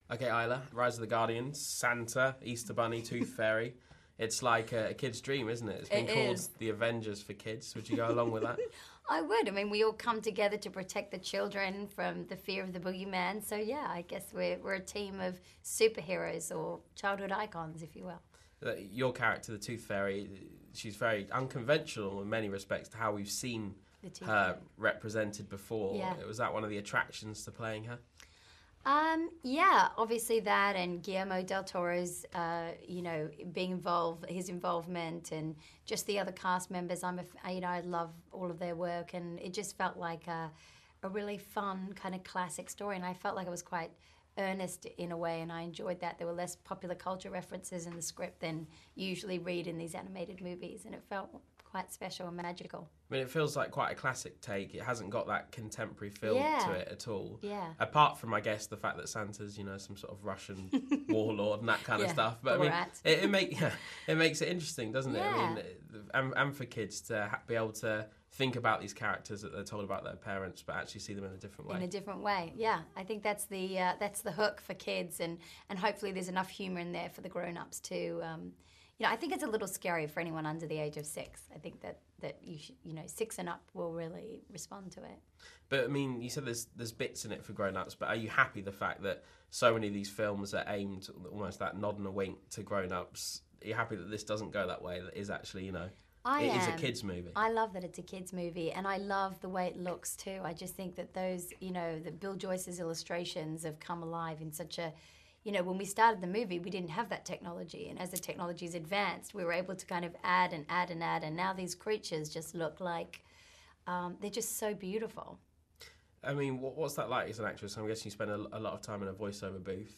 Isla Fisher talks to Sky News Radio about Rise of the Guardians